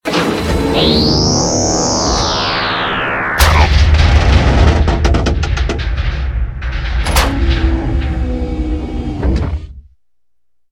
clamps.ogg